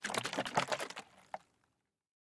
Звуки пульверизатора
Звук воды в пульверизаторе трясем распылитель